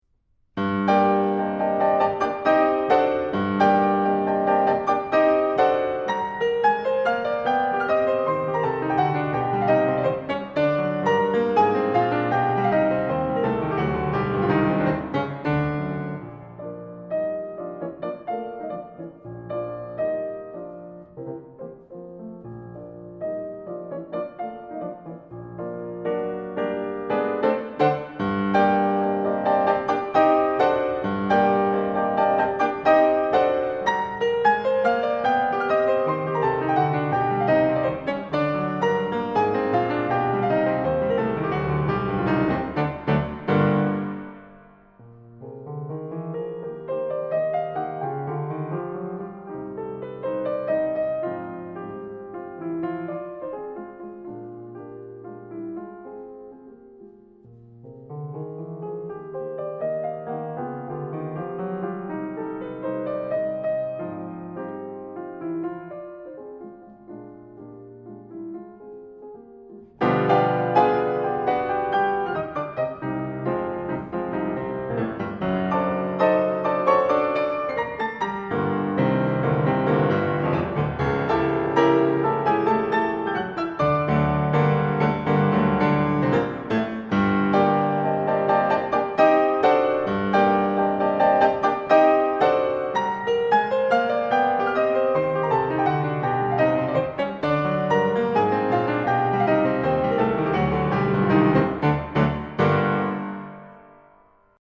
Robert Schumann: Piano Sonata no. 2 in G minor op. 22. 3. Scherzo